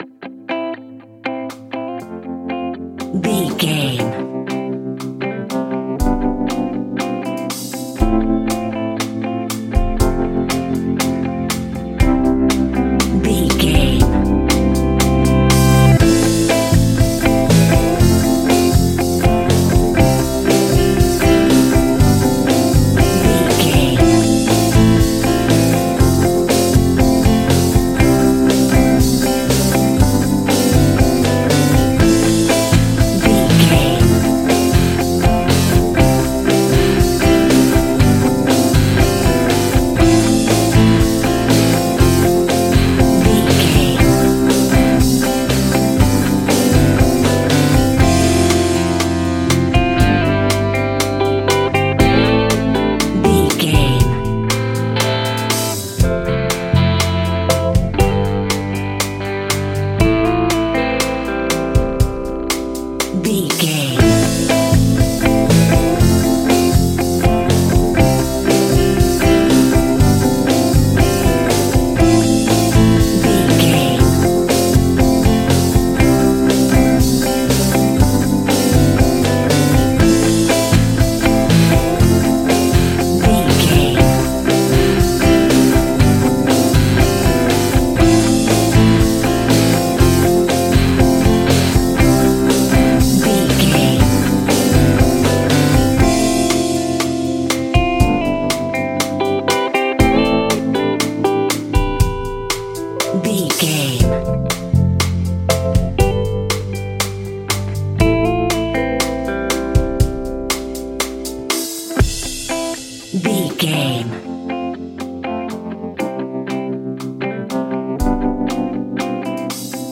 Uplifting
Ionian/Major
2000s
indie pop rock instrumentals
guitars
bass
drums
piano
organ